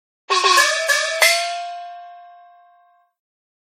Gong.ogg